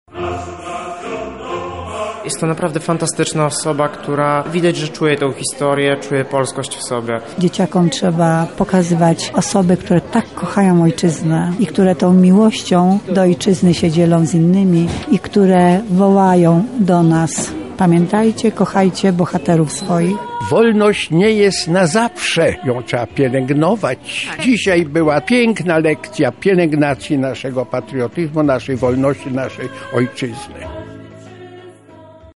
Wczoraj w Teatrze Muzycznym opowiadała o ciekawostkach z życia Żołnierzy batalionu „Zośka” , jednego z najbardziej zasłużonych i docenionych oddziałów, walczących za czasów okupacji.